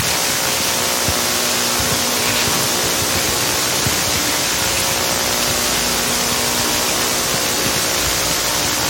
noise_pc.m4a